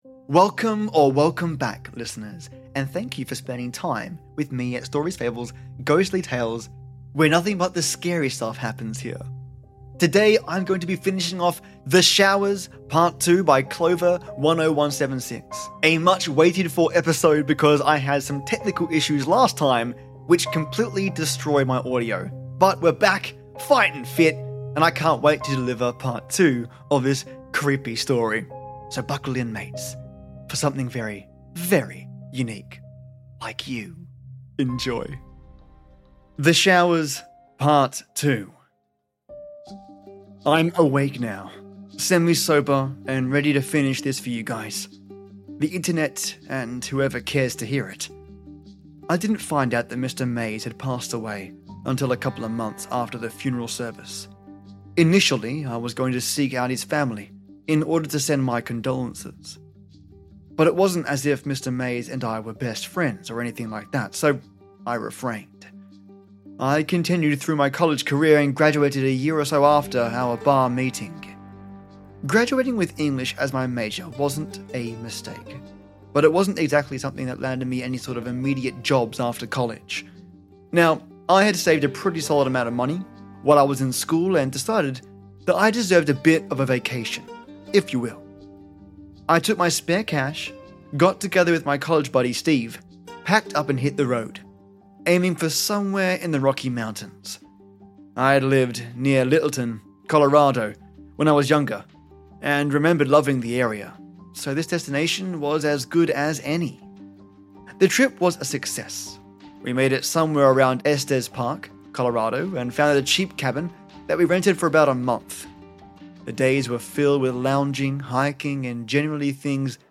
Today I bring you a mix of Synthwave horror and an adventure into the unknown....but mainly horror. I've focused on working the atmosphere, pulling on some sound effects and tweaking soundscapes.